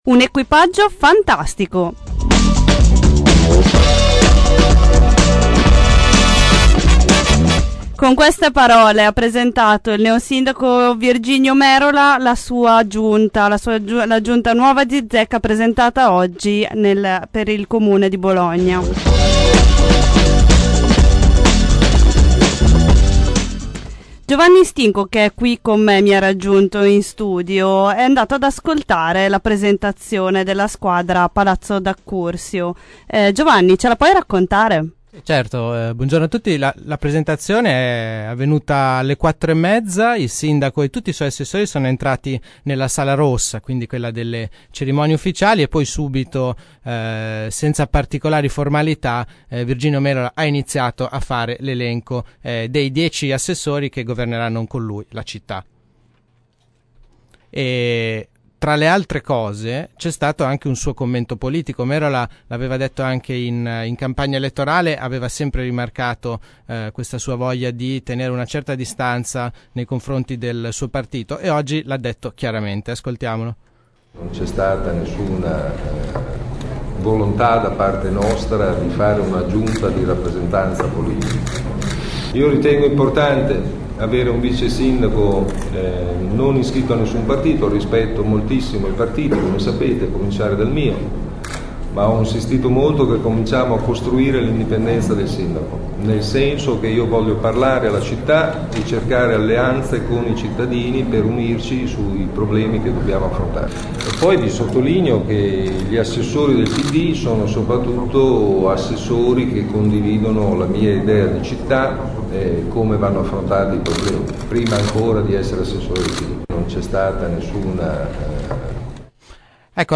Ascolta le voci dei nuovi assessori trasmesse durante passenger